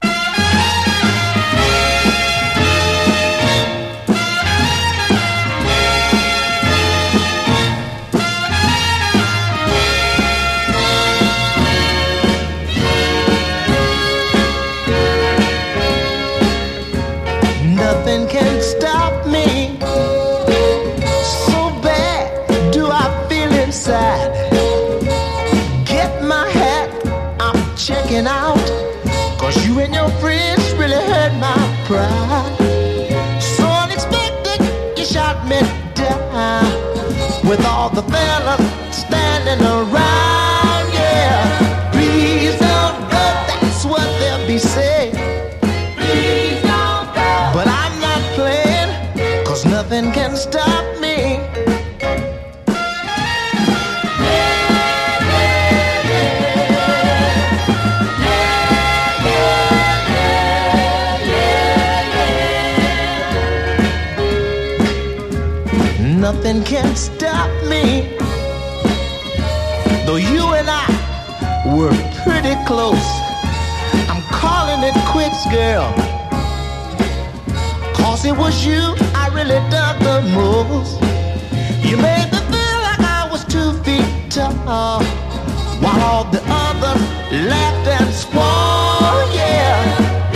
黄金のシカゴ・ノーザンソウル・クラシック！